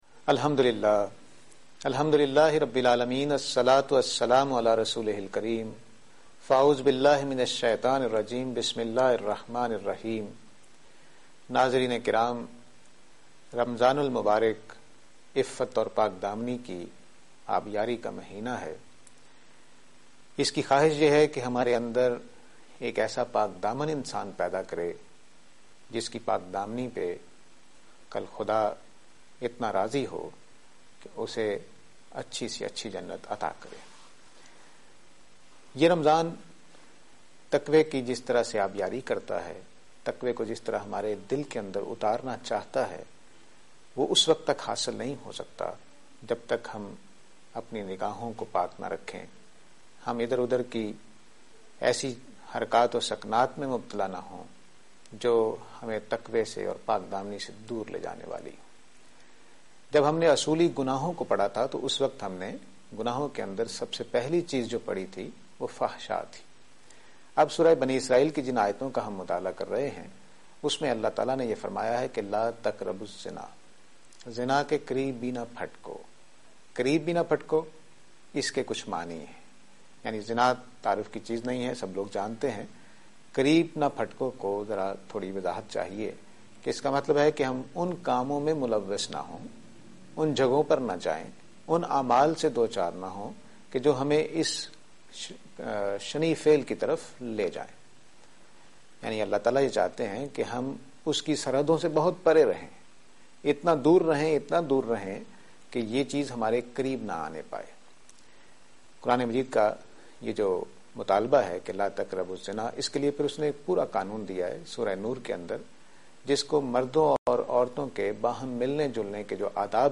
Program Tazkiya-e-Ikhlaq on Aaj Tv.